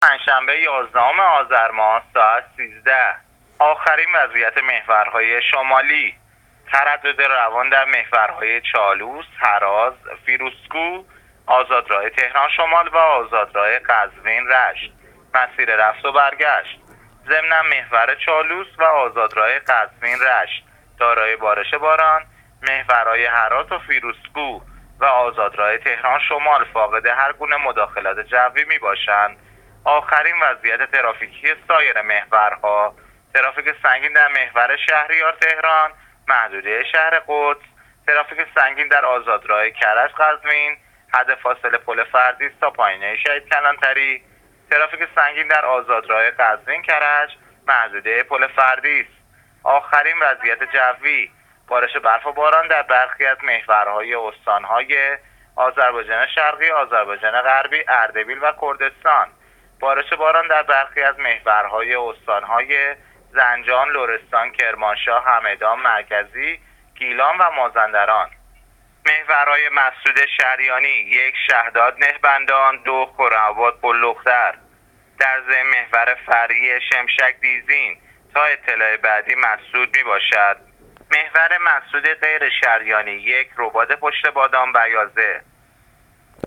گزارش رادیو اینترنتی از آخرین وضعیت ترافیکی جاده‌ها تا ساعت ۱۳ یازدهم آذر؛